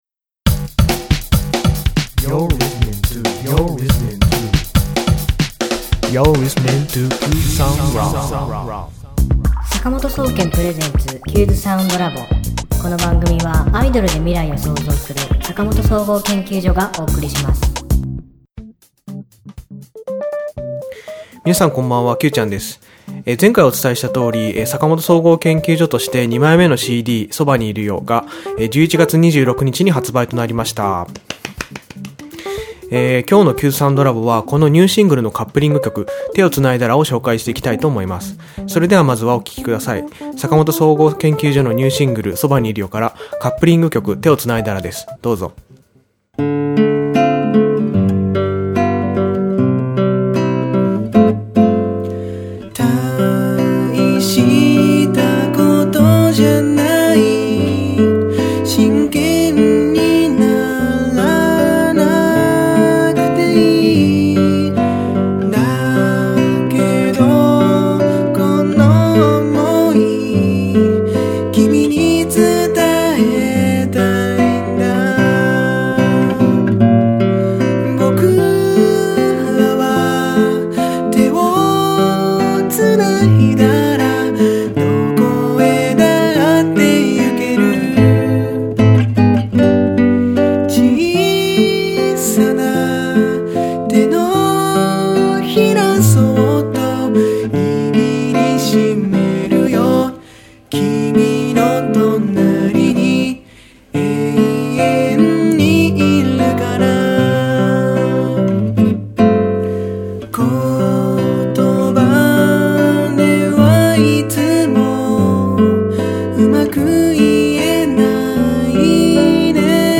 今週の挿入歌
ギター
アコースティックな弾き語りによる究極のラブソング